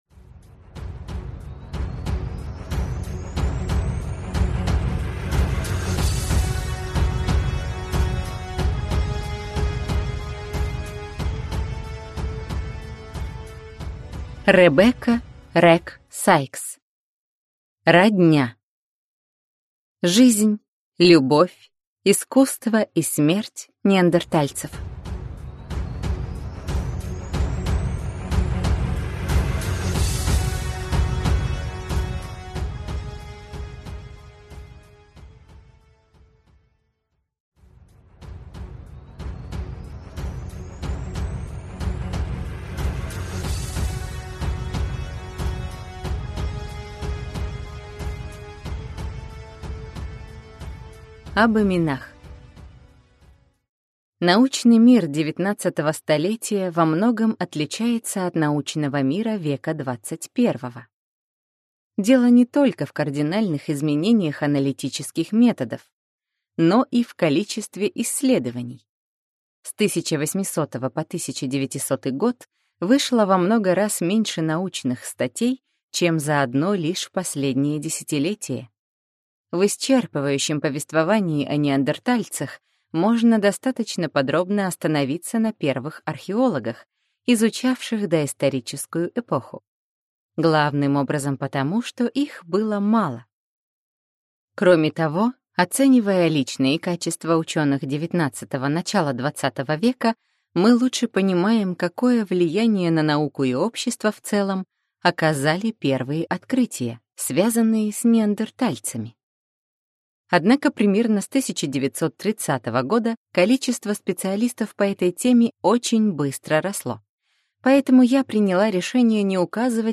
Аудиокнига Родня: жизнь, любовь, искусство и смерть неандертальцев | Библиотека аудиокниг